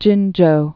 (jĭnjō)